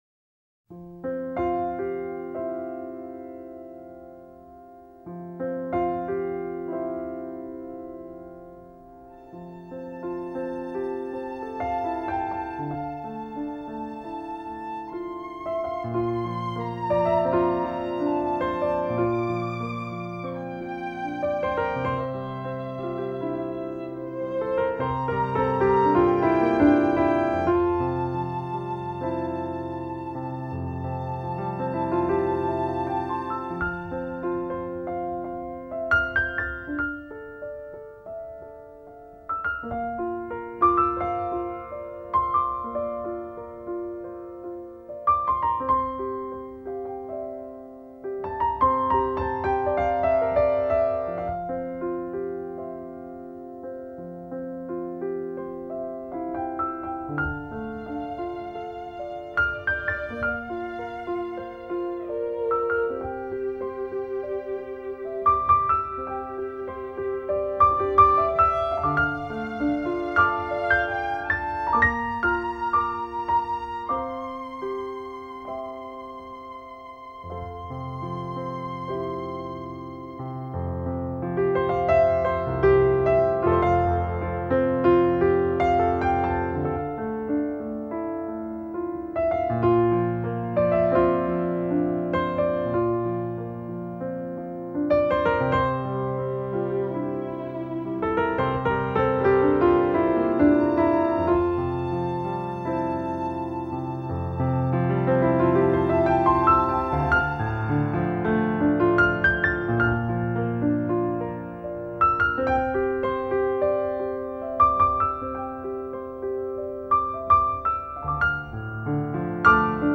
. 九位钢琴大师浪漫唯心主义
这里或许更多些感情色彩，浓浓的柔情和复杂的心理变化，平缓的起伏间，诱发了我们的对于过去的记忆的如流水般的重现。